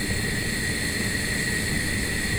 Gas Burn Loop 01.wav